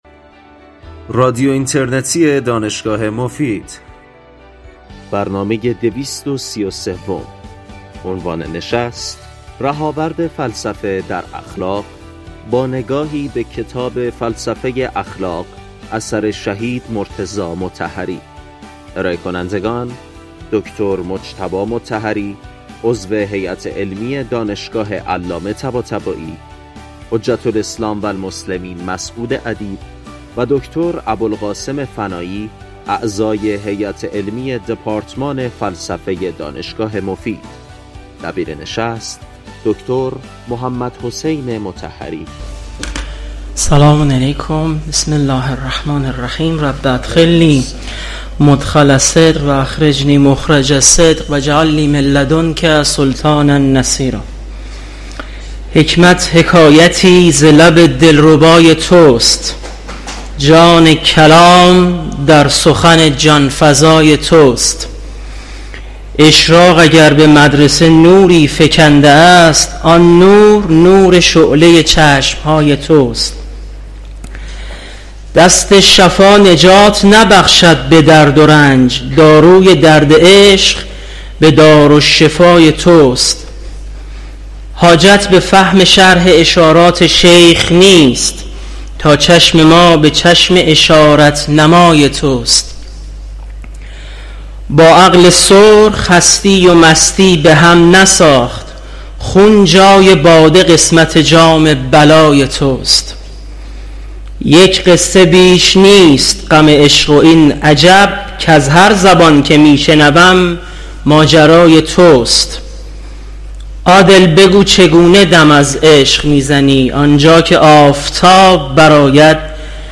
این برنامه در سال ۱۴۰۳ و با ابتکار فستیوال فلسفی دانشگاه مفید برگزار شده است.